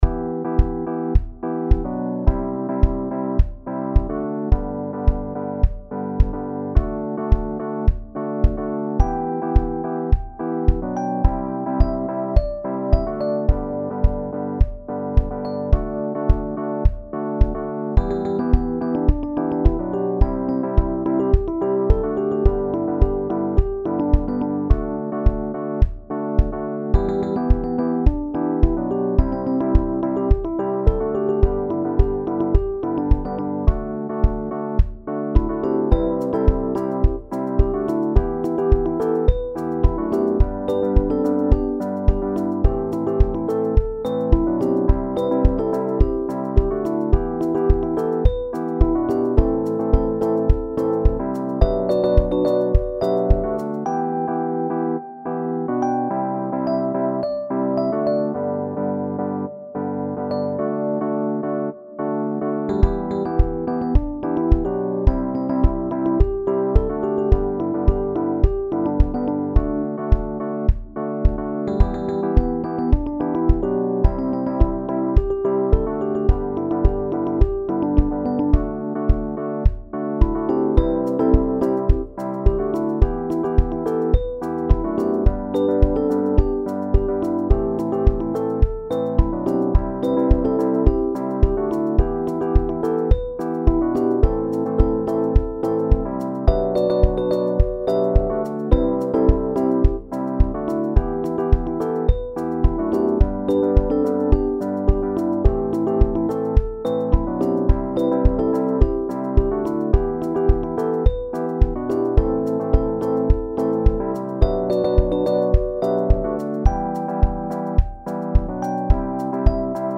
SATB met solo